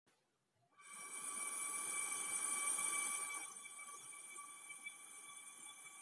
Wat een bijzonder geluid!